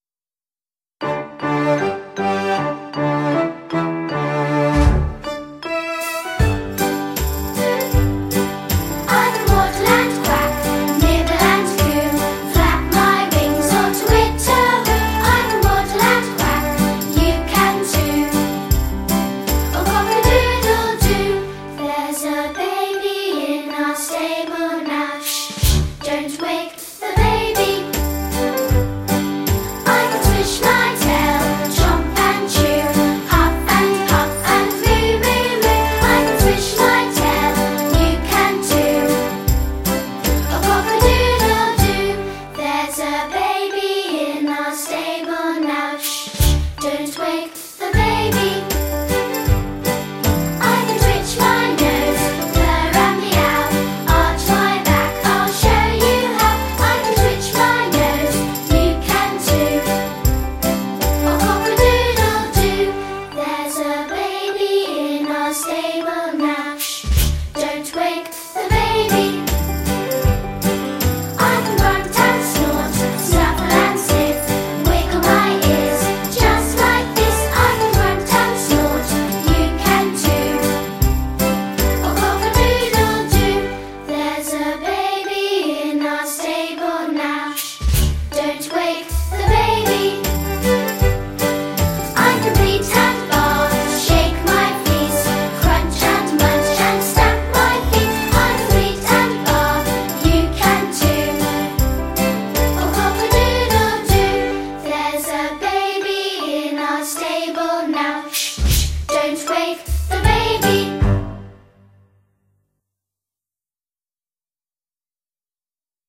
Christmas Songs to Practise